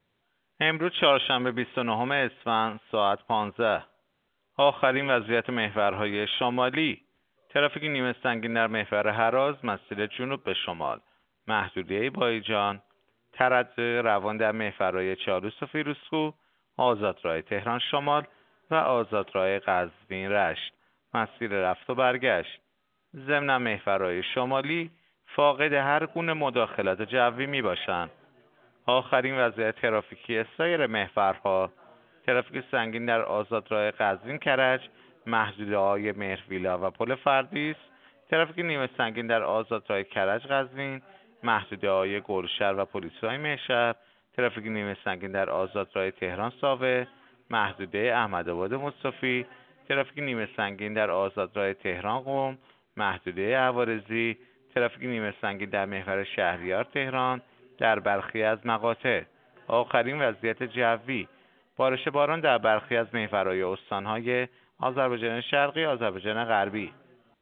گزارش رادیو اینترنتی از آخرین وضعیت ترافیکی جاده‌ها ساعت ۱۵:۱۵ بیست و نهم اسفند؛